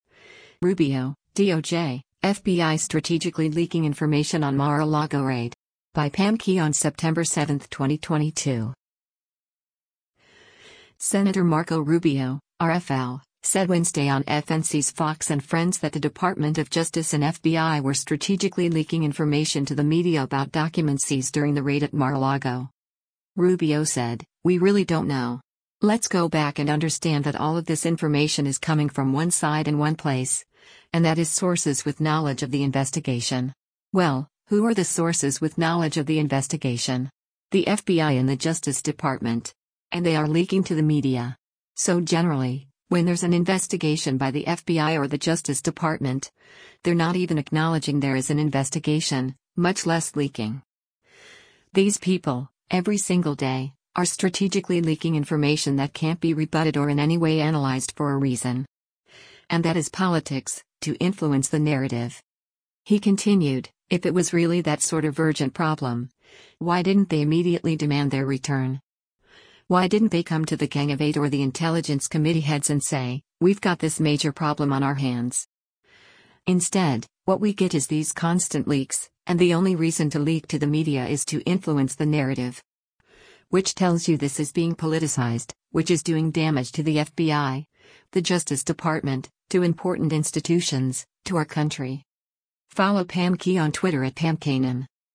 Senator Marco Rubio (R-FL) said Wednesday on FNC’s “Fox & Friends” that the Department of Justice and FBI were “strategically leaking information” to the media about documents seized during the raid at Mar-a-Lago.